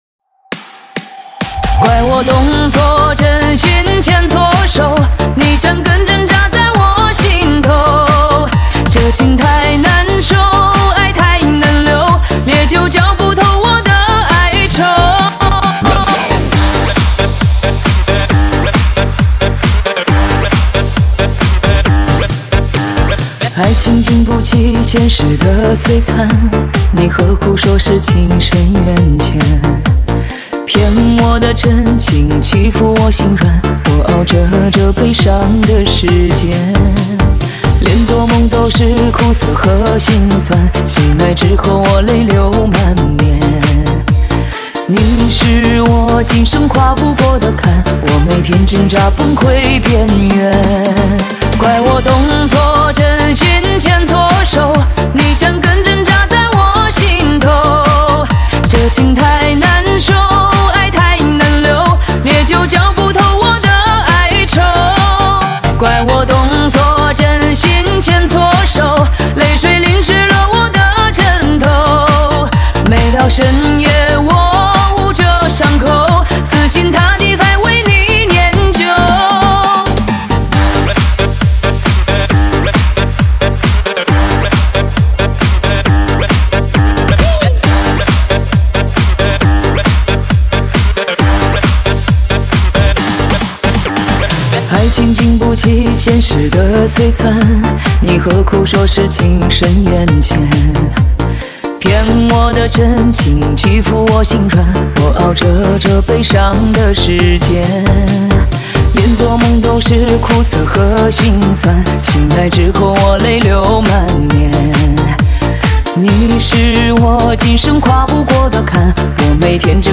舞曲类别：中文慢摇